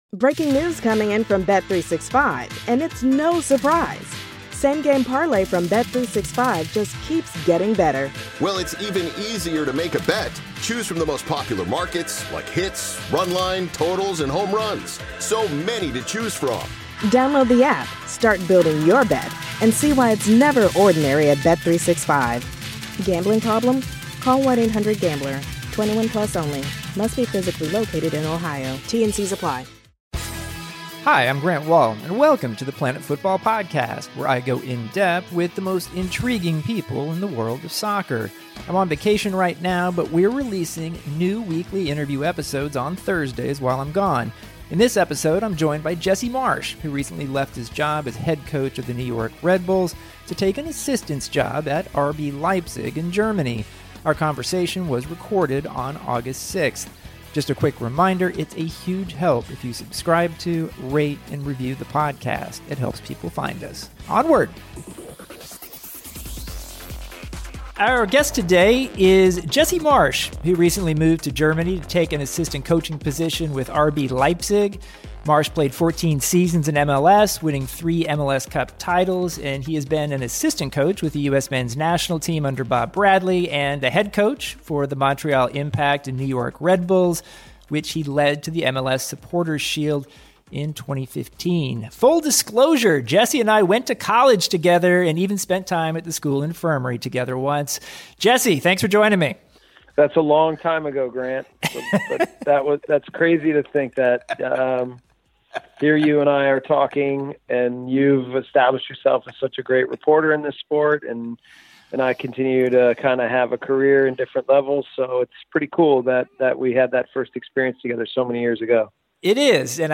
Grant interviews Jesse Marsch, who recently left his job as head coach of the New York Red Bulls to take an assistant coaching job with RB Leipzig in the German Bundesliga. Marsch explains why he took the Leipzig job, how he has assimilated quickly and how he feels about the open U.S. men’s coaching job.